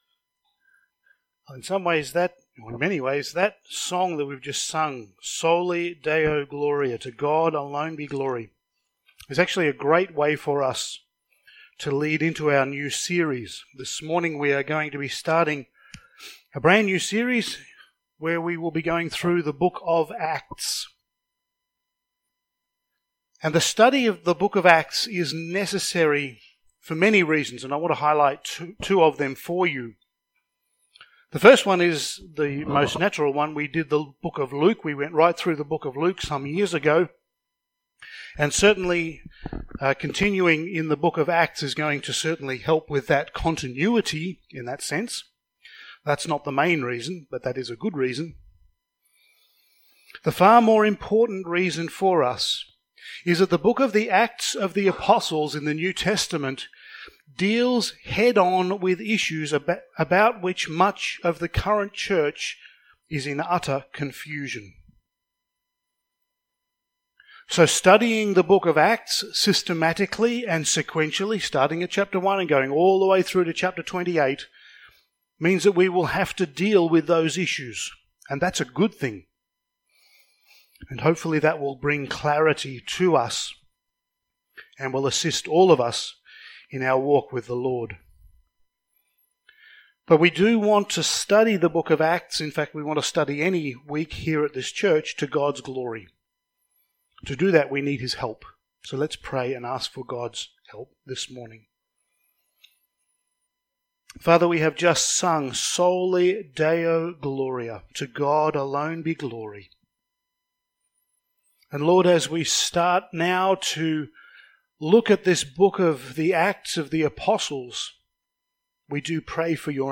Passage: Acts 1:1-2 Service Type: Sunday Morning